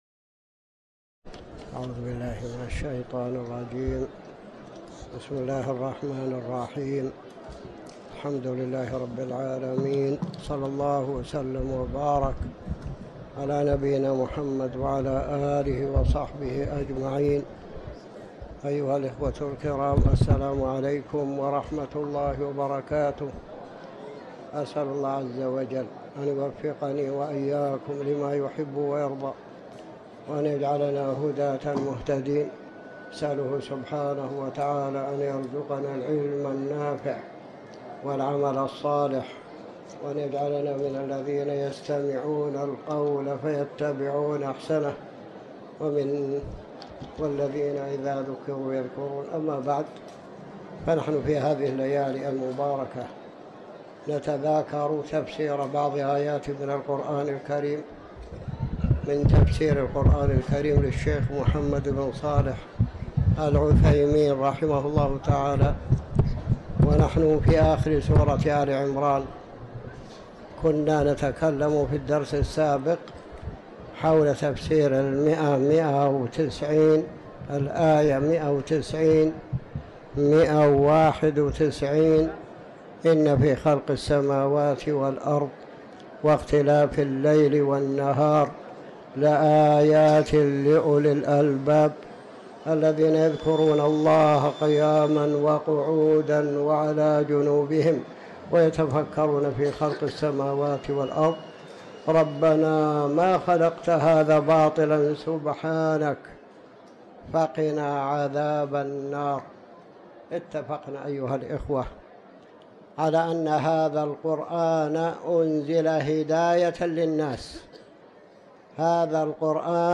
تاريخ النشر ٢٣ رجب ١٤٤٠ هـ المكان: المسجد الحرام الشيخ